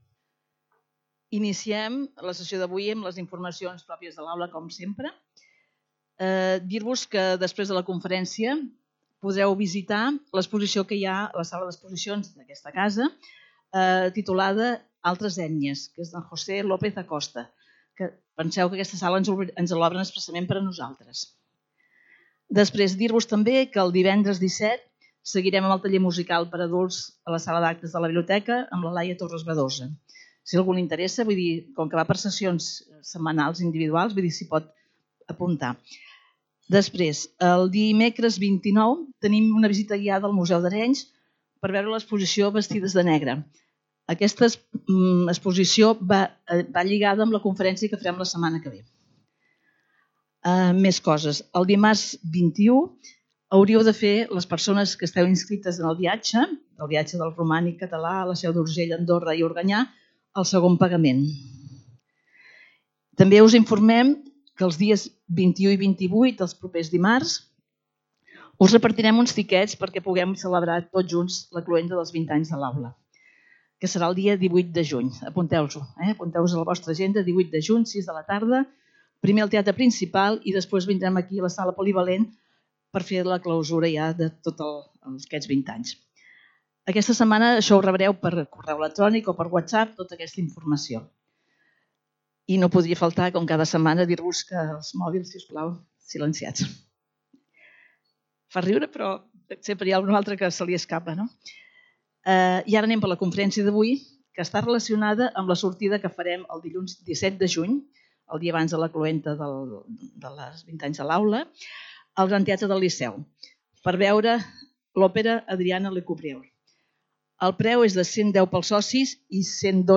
Lloc: Centre Cultural Calisay
Conferències